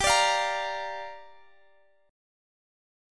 Listen to Gm7 strummed